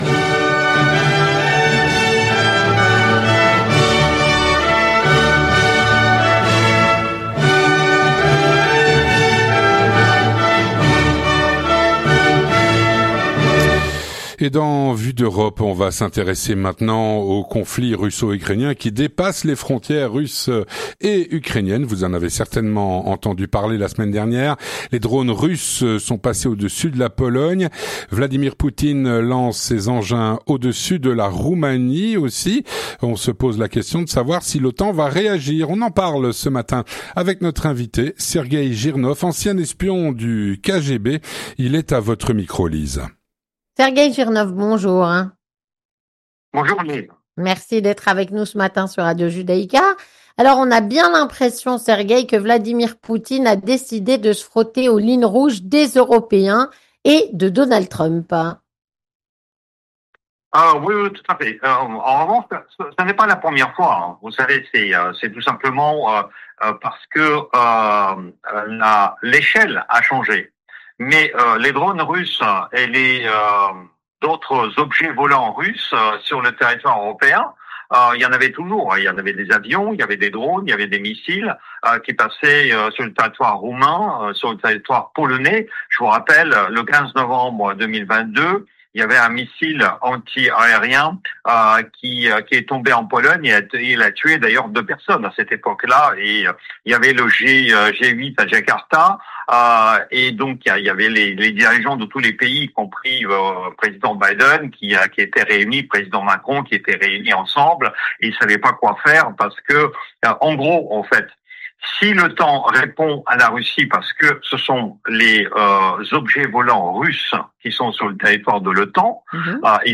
On en parle avec Sergueï Jirnov, ancien espion du KGB et auteur de nombreux livres.